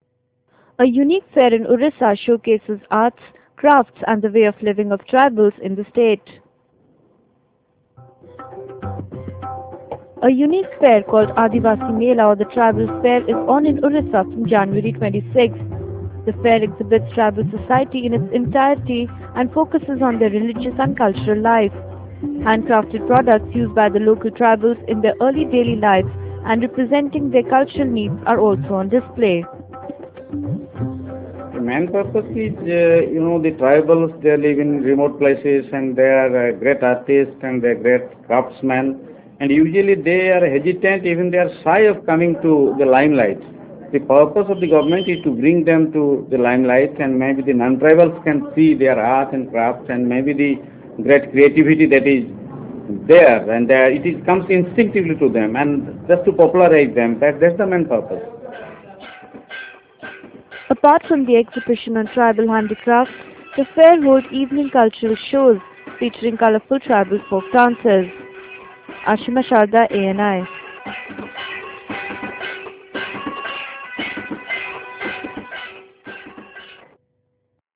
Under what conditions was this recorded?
A unique fair called "Adivasi Mela" or the tribal fair is on in Orissa from January 26.